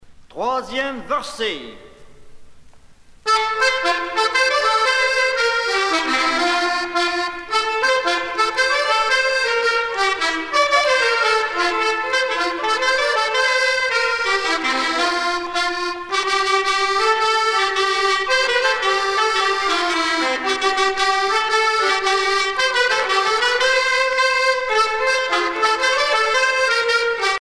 danse : quadrille